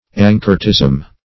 Anchoretism \An"cho*ret*ism\, n. The practice or mode of life of an anchoret.
anchoretism.mp3